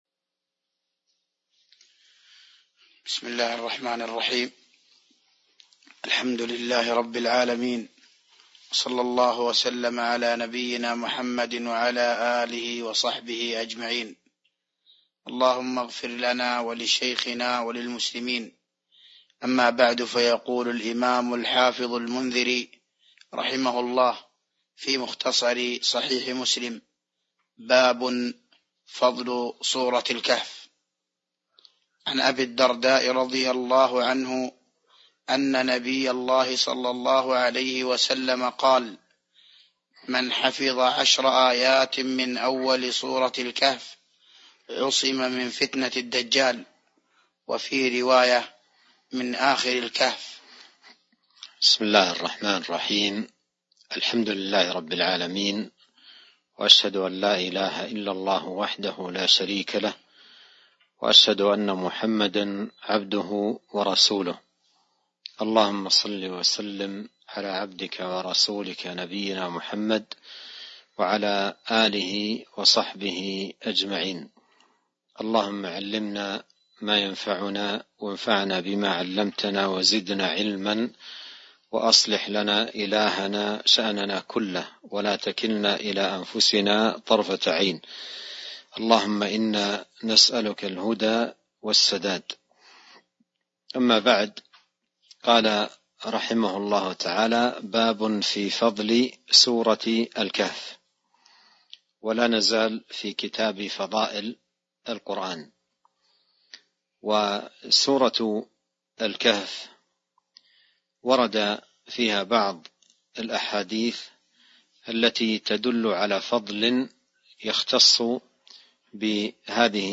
تاريخ النشر ٥ رمضان ١٤٤٢ هـ المكان: المسجد النبوي الشيخ